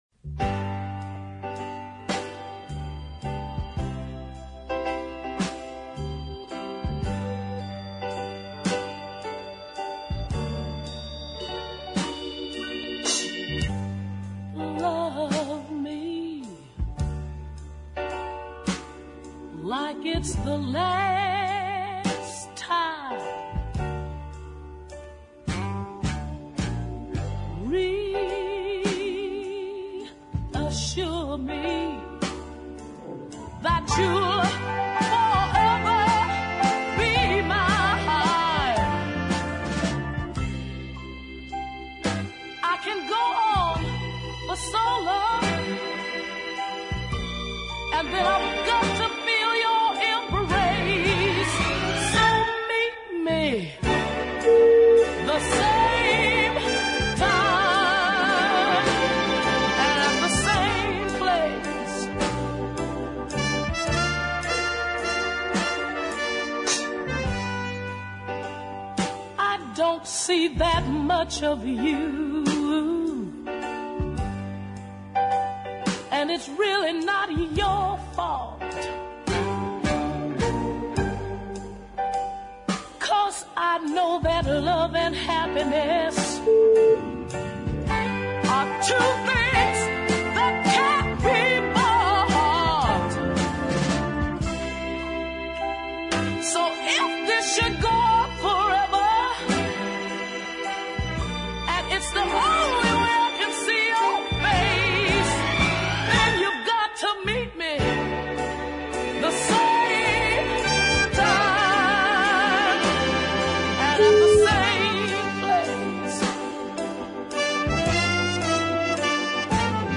singer/percussionist
superbly soulful version
just check out those swirling strings